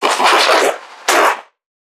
NPC_Creatures_Vocalisations_Infected [61].wav